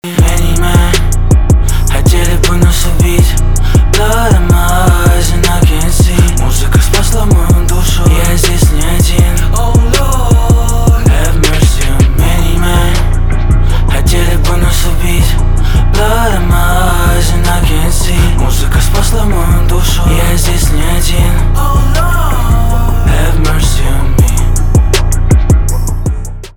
русский рэп , пианино , жесткие , битовые , басы